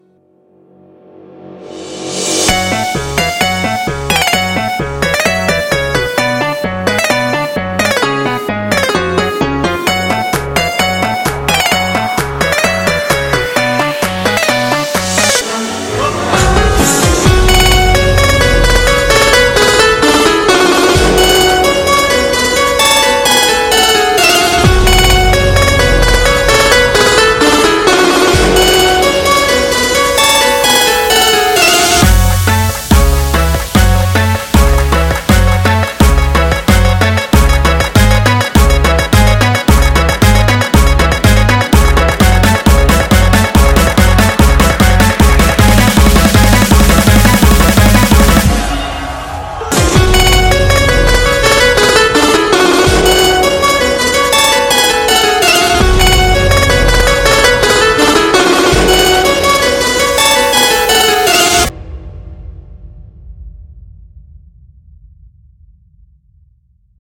громкий энергичный рингтон Ура!